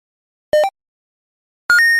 Free UI/UX sound effect: Power Up.
369_power_up.mp3